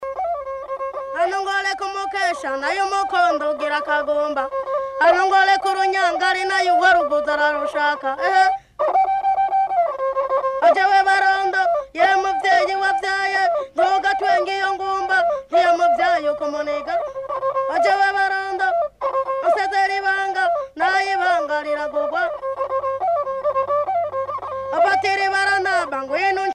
Complainte à la vièle monocorde
vièle monocorde
enregistrée près de la capitale Bujumbura